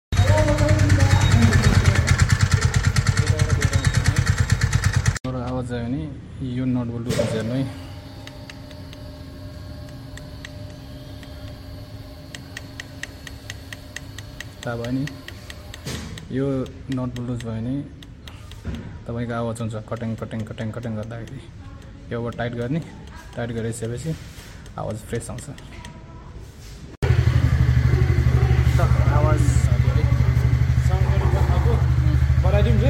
Dherai jaso tvs Ntorq scooty Ma engine bata naramro sound Aunxa.